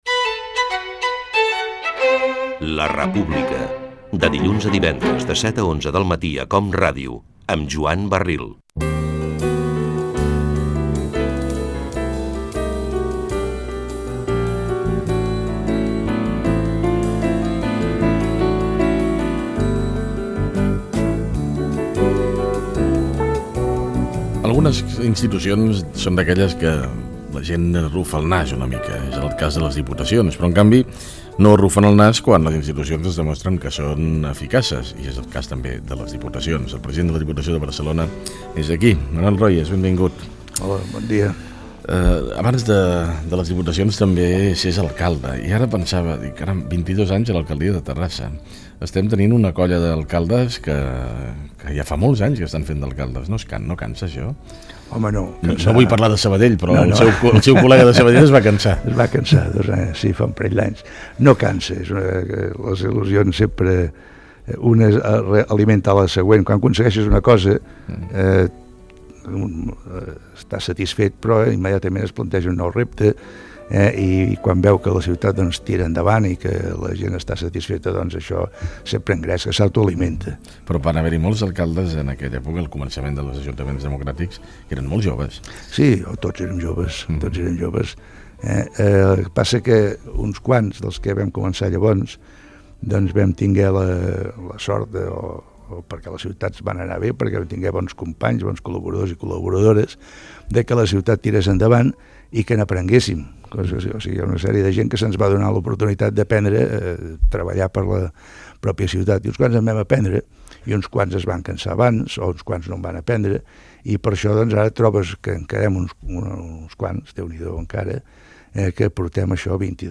Identificació del programa, fragment d'una entrevista al president de la Diputació de Barcelona, Manuel Royes
Info-entreteniment